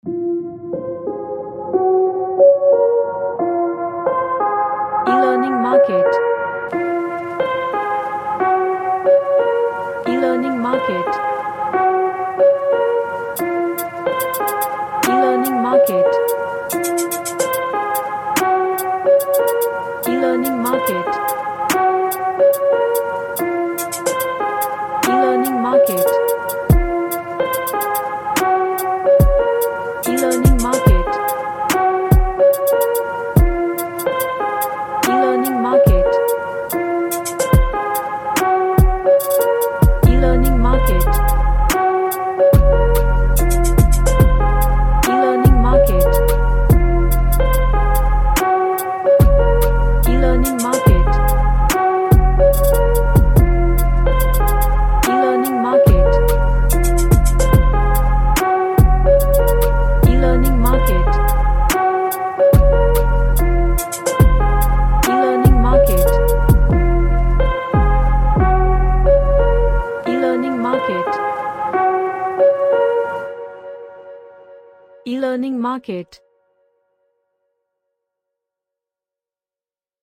A hard trap beat
Suspense / DramaDark